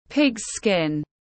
Da lợn tiếng anh gọi là pig’s skin, phiên âm tiếng anh đọc là /pɪgz skɪn/
Pig’s skin /pɪgz skɪn/